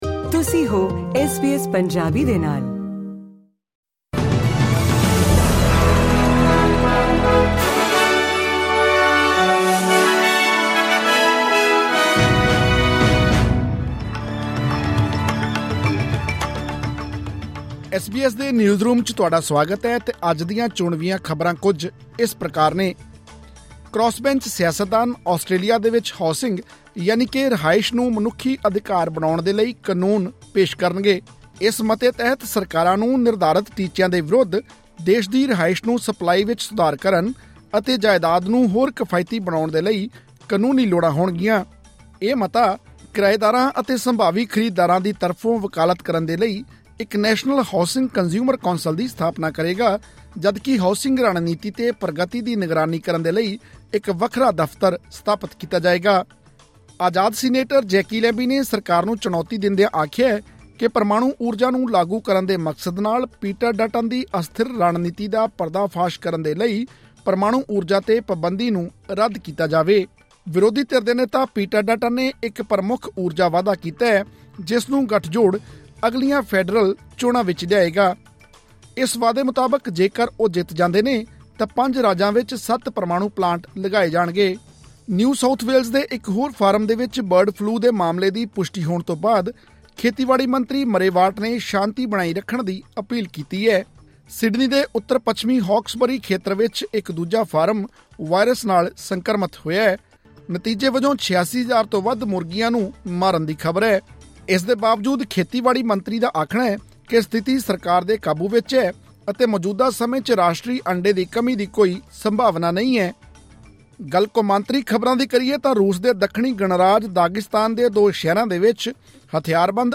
ਐਸ ਬੀ ਐਸ ਪੰਜਾਬੀ ਤੋਂ ਆਸਟ੍ਰੇਲੀਆ ਦੀਆਂ ਮੁੱਖ ਖ਼ਬਰਾਂ: 24 ਜੂਨ 2024